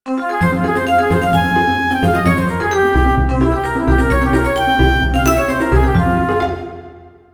Tonalidad de Fa menor. Ejemplo.
tristeza
dramatismo
melodía
severo
sintetizador